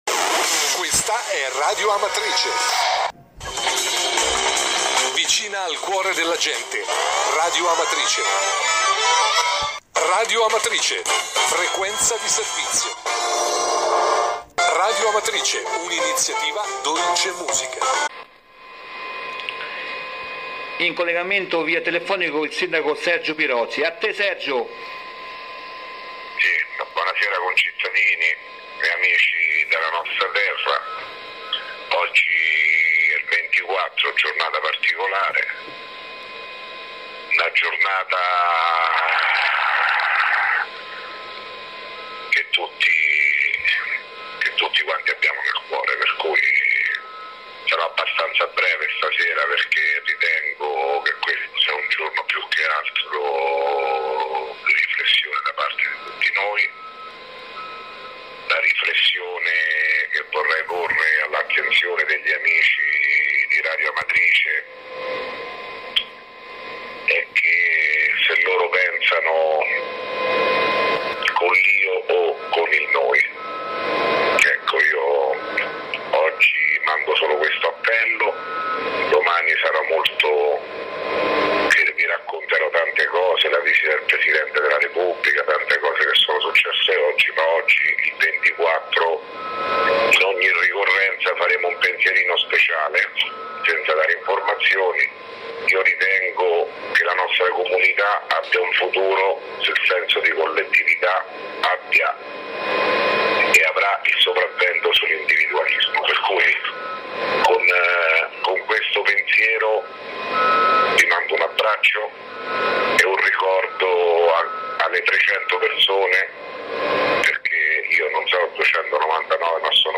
Di seguito il comunicato audio del Sindaco Sergio Pirozzi, del 24 novembre 2016.